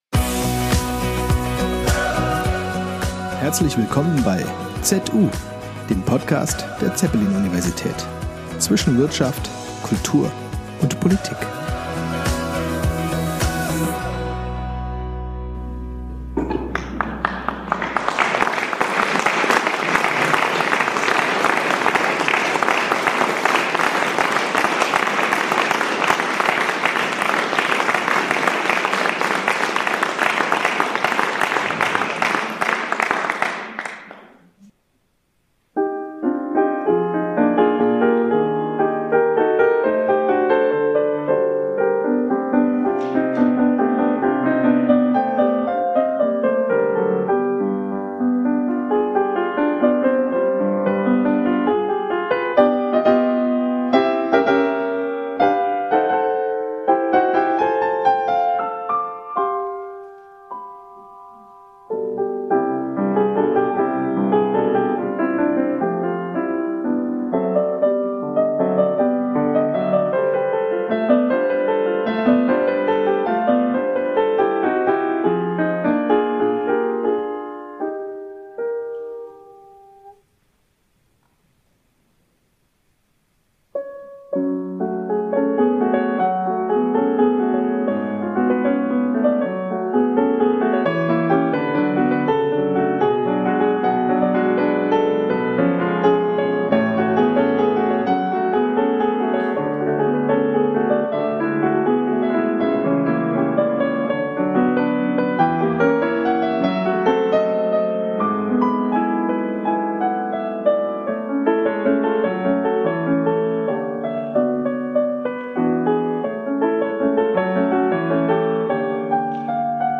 Gesprächskonzert
Klavierstücken